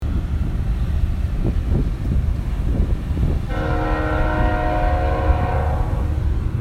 Kansas City train
Recorded entirely on an iPhone 6s, the album is designed to be listened to in a single sitting, it’s 23 tracks blending continuously to unfurl the story of a unique journey around the world.